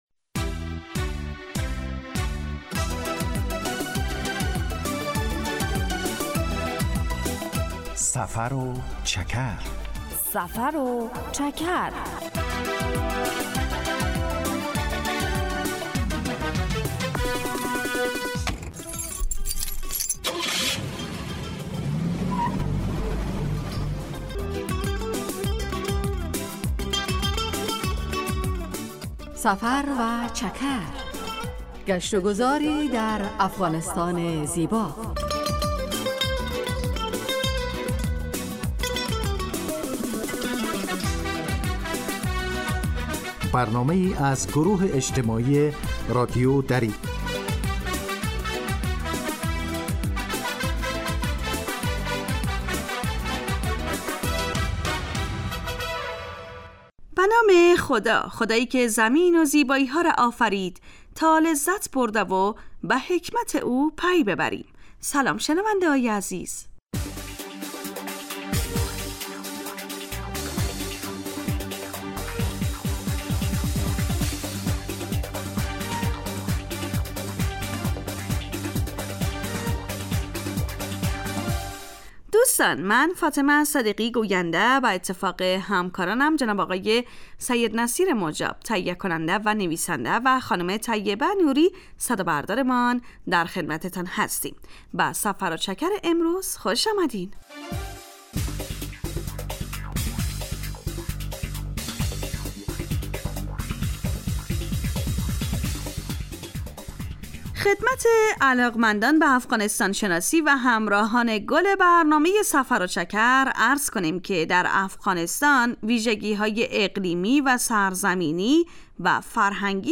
سفر و چکر برنامه ای 15 دقیقه از نشرات رادیو دری است که به معرفی ولایات و مناطق مختلف افغانستان می پردازد. در این برنامه مخاطبان با جغرافیای شهری و فرهنگ و آداب و سنن افغانی آشنا می شوند. در سفر و چکر ؛ علاوه بر معلومات مفید، گزارش و گفتگو های جالب و آهنگ های متناسب هم تقدیم می شود.